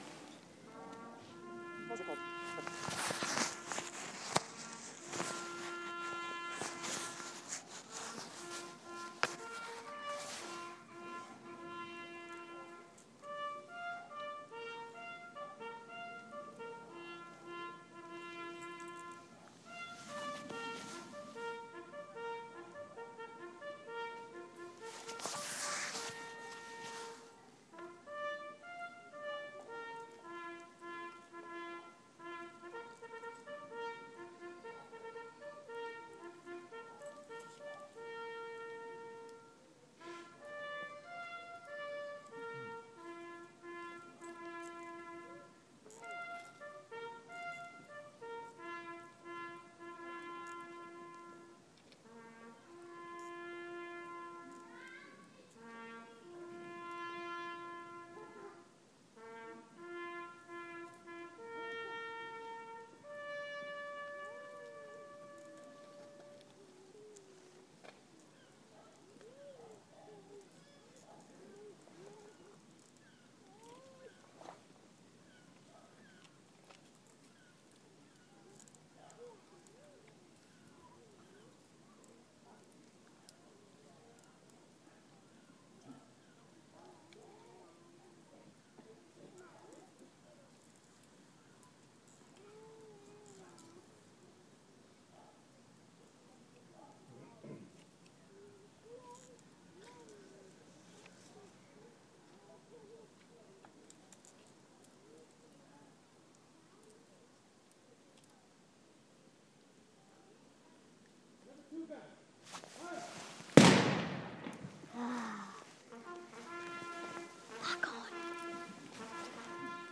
5 minutes of the rememberence service in Oakdale village square.